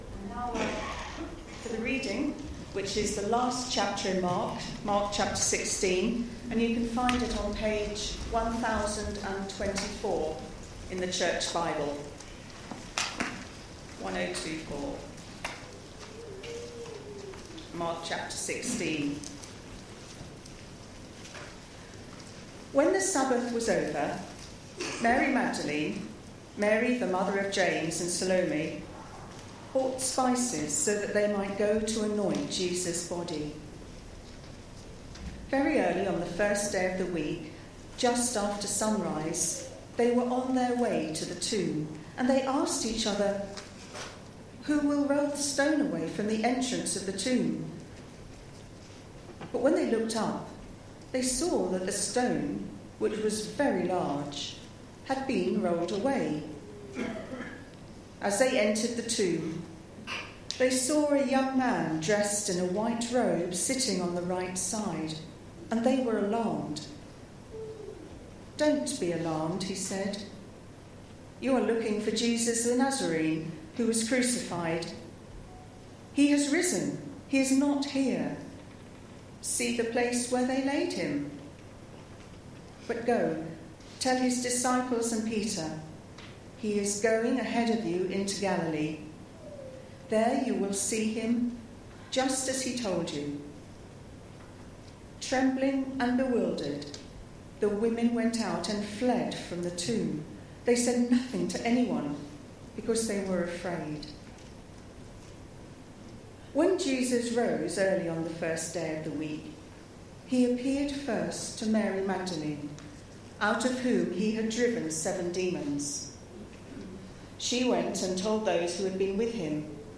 Series: The Gospel of Mark Theme: Resurrection Sermon